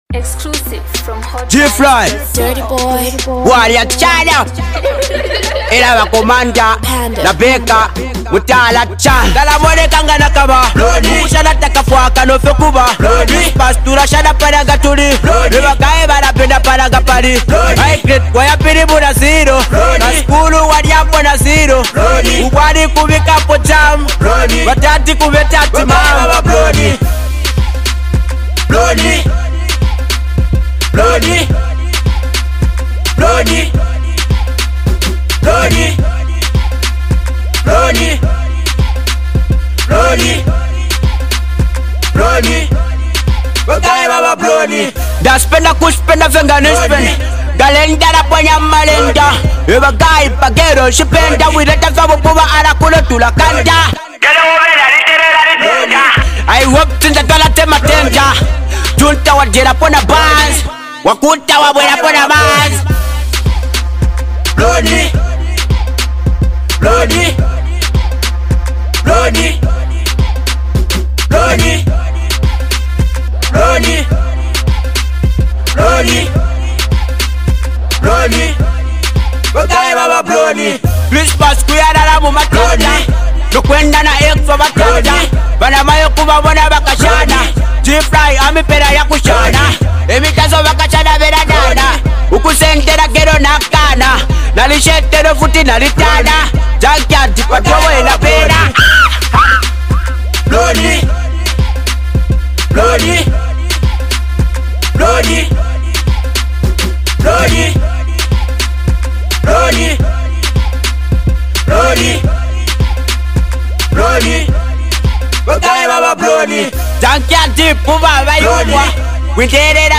Budding rapper
hot jam